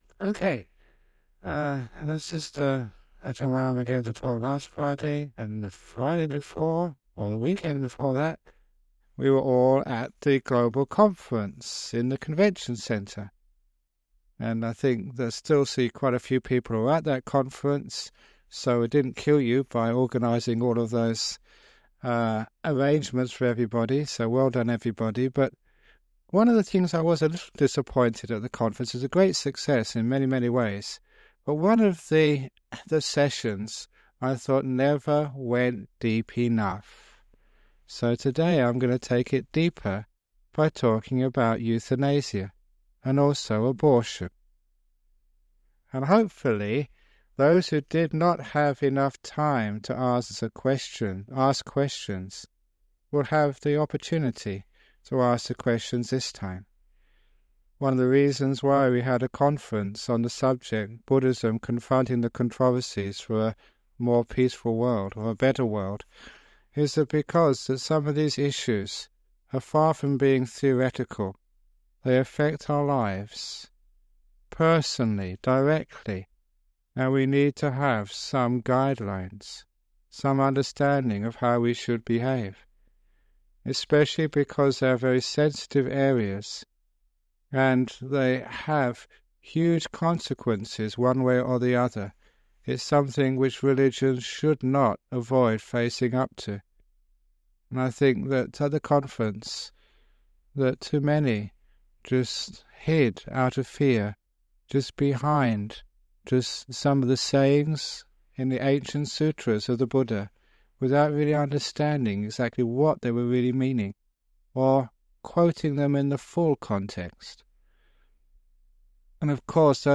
Ajahn Brahm explains…—This dhamma talk was originally recorded using a low quality MP3 to save on file size on 7th April 2006.